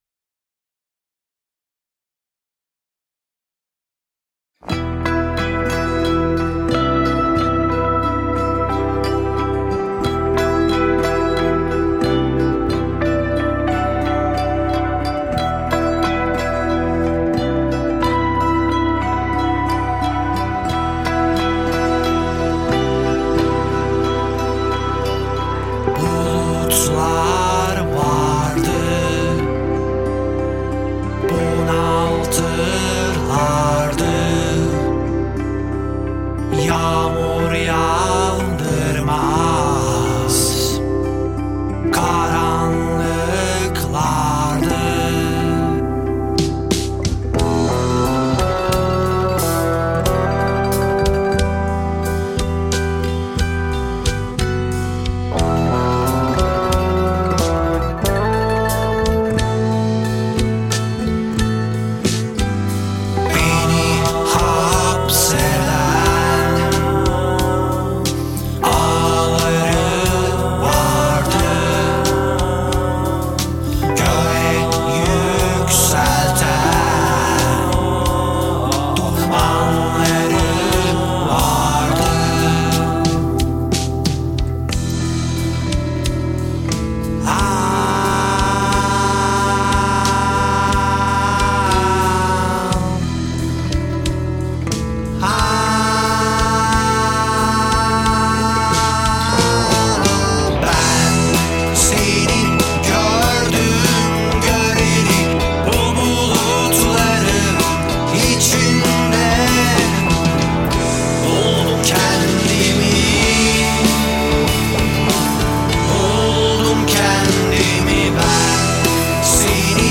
Mixture: Producción de un EP de fusión entre rock progresivo, gaita, flauta turca, acordeón, congas y mandolina